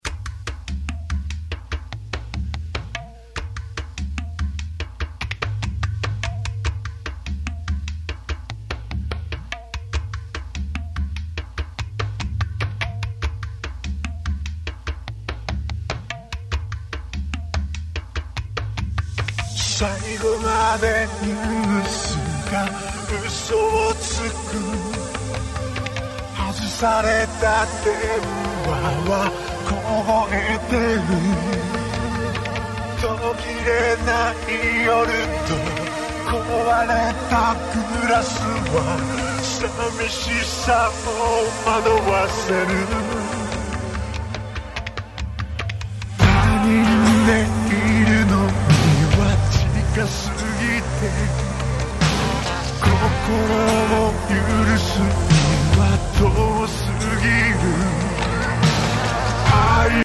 結果：それっぽくなったけど，やはりワウフラが許容範囲とは言えない → 失敗